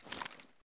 knife_hide.ogg